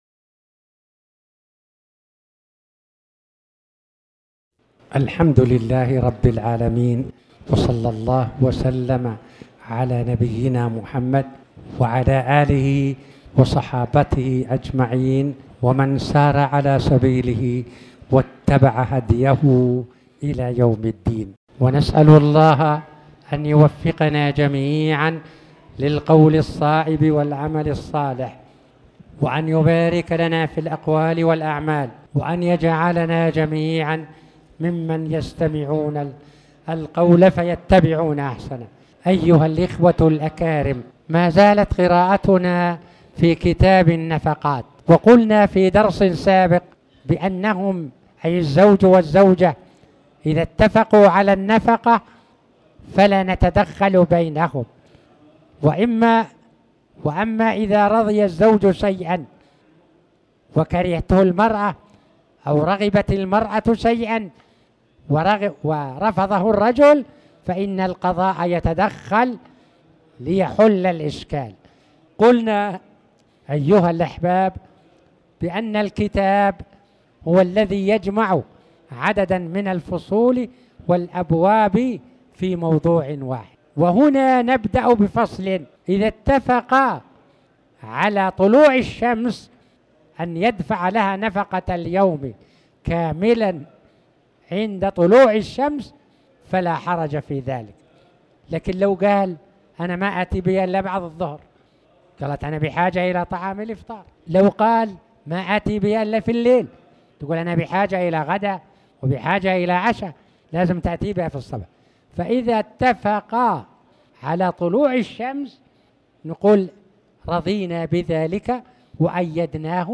تاريخ النشر ١١ جمادى الآخرة ١٤٣٩ هـ المكان: المسجد الحرام الشيخ